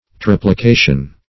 triplication - definition of triplication - synonyms, pronunciation, spelling from Free Dictionary
Triplication \Trip"li*ca"tion\, n. [L. triplicatio: cf. F.